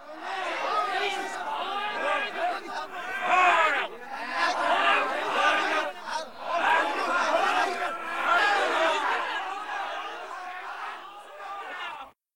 环境音 / 非循环音(SE)
0011_骂声四溢.ogg